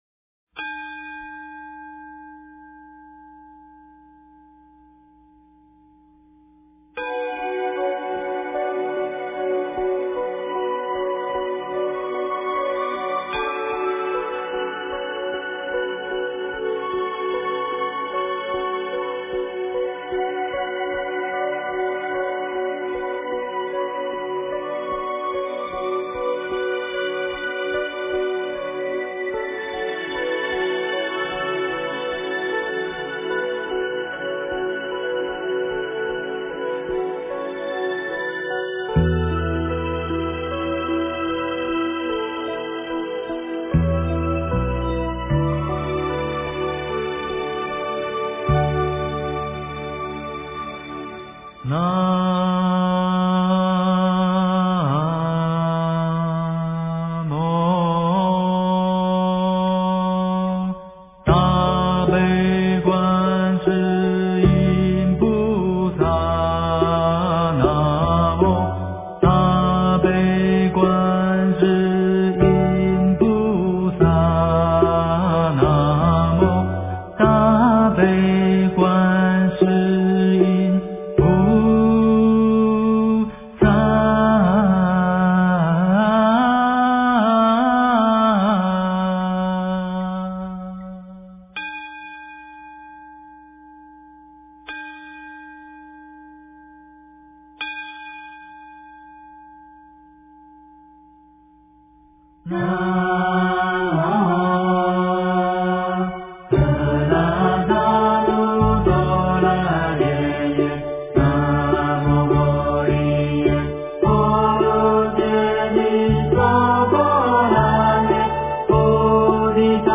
大悲神咒 诵经 大悲神咒--梵呗精选 点我： 标签: 佛音 诵经 佛教音乐 返回列表 上一篇： 极乐净土 下一篇： 放下难舍的缘 相关文章 弥陀圣号千华调--佛光山 弥陀圣号千华调--佛光山...